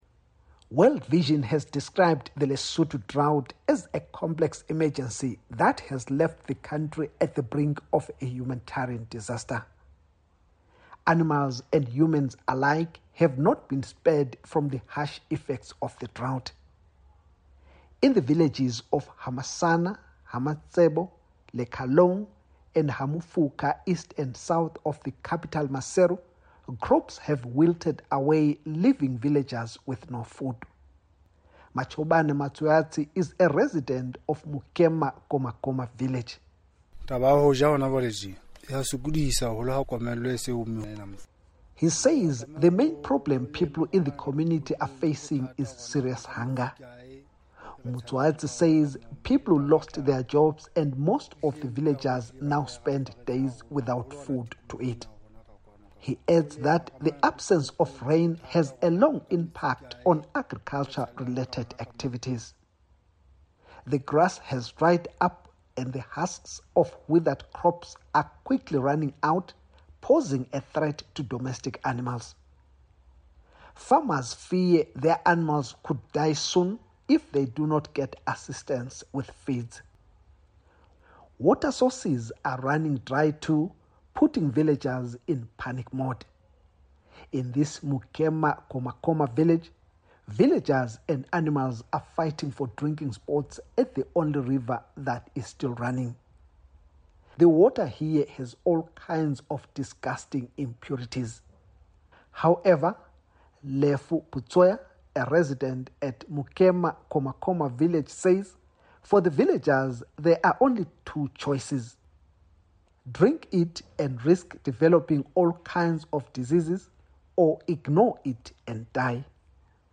Africa News Tonight Clips